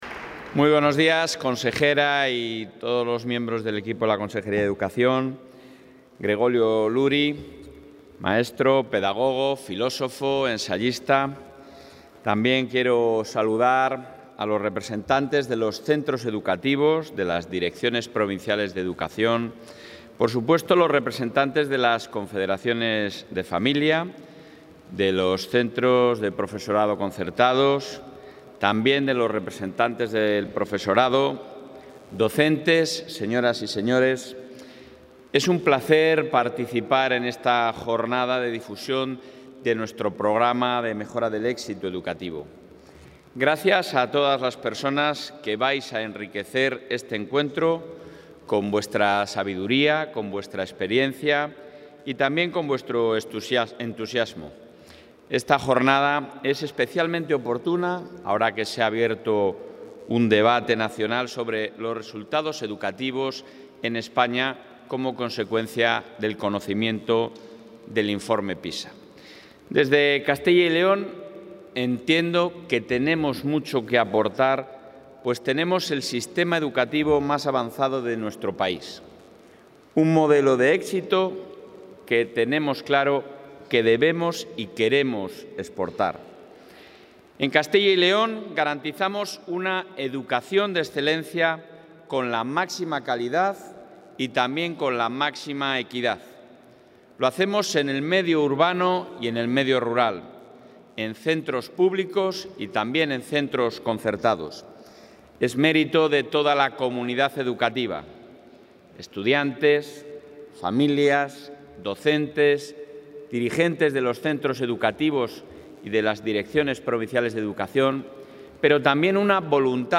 Intervención del presidente de la Junta.
En la Jornada de Difusión para la Mejora del Éxito Educativo, el presidente de la Junta de Castilla y León, Alfonso Fernández Mañueco, ha vuelto a destacar que la Comunidad tiene el sistema educativo más avanzado de España, en el medio rural y urbano; ha animado a toda la comunidad educativa a seguir perseverando para mantener a la Comunidad a la vanguardia de la calidad y la equidad; y ha insistido en la necesidad de una EBAU única que garantice la igualdad de oportunidades en todo el país.